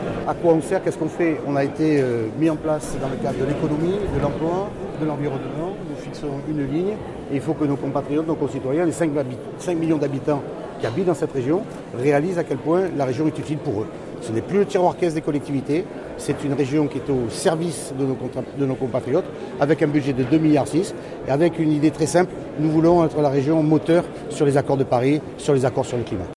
Les journalistes étaient présents en nombre pour les vœux à la presse de Renaud Muselier.